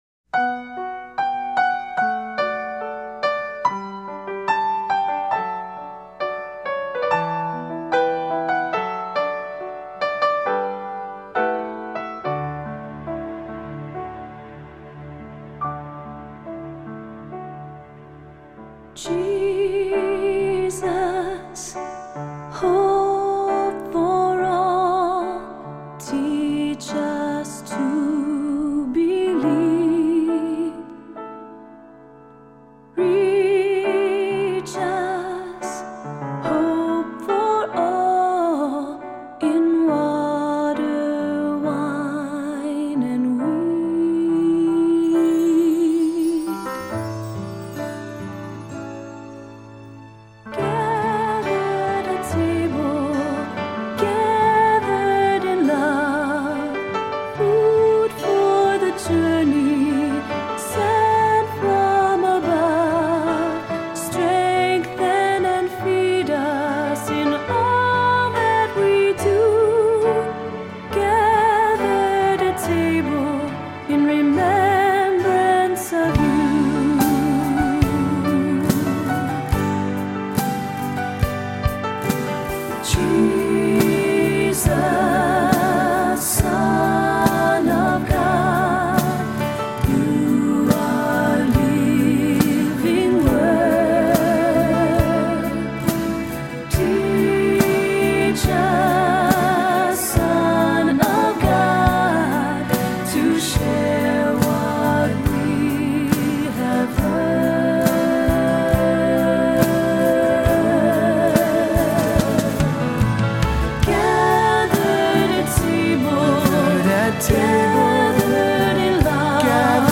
Voicing: Three-part equal; Assembly